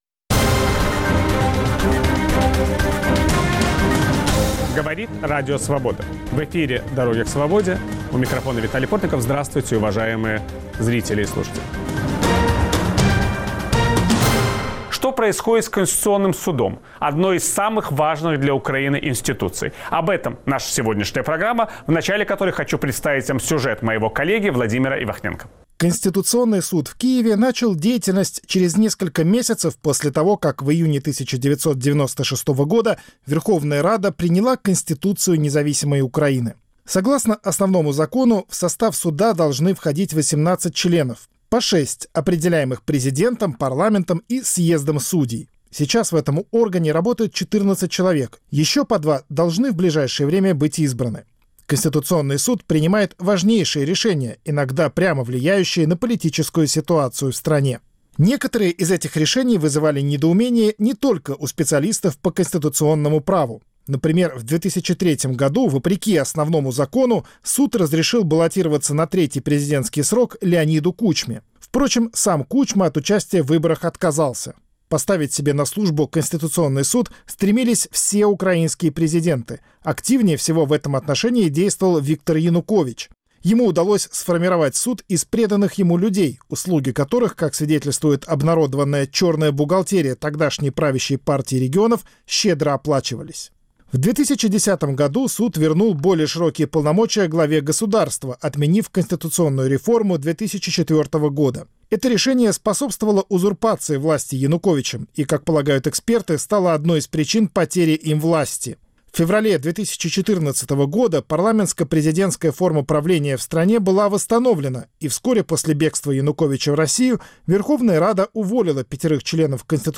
Собеседник Виталия Портникова — профессор, бывший заместитель председателя Верховной Рады Украины Виктор Мусияка